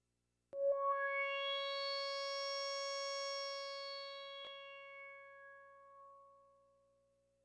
描述：通过Modular Sample从模拟合成器采样的单音。
标签： CSharp6 MIDI音符-85 罗兰木星-4 合成器 SINGL电子笔记 多重采样
声道立体声